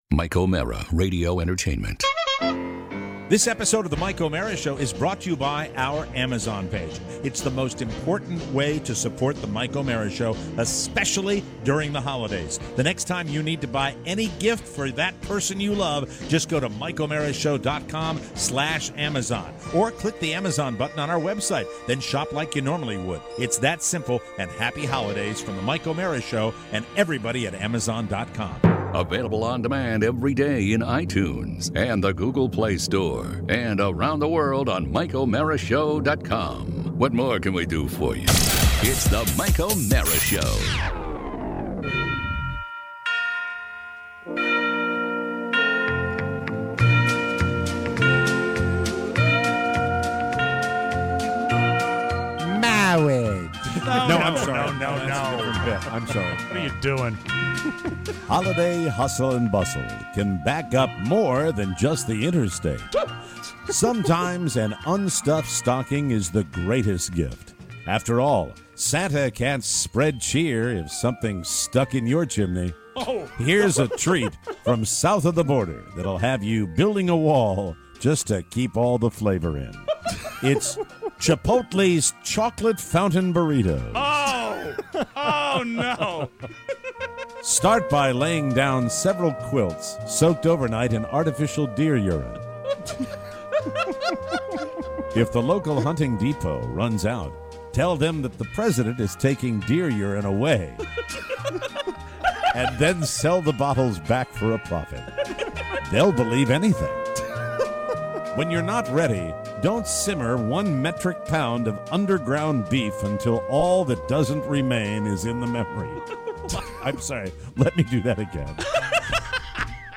Christmas magic fills the studio! Kraft tastiness, Star Wars attendance, costumes, Jeff Rossen and gifts.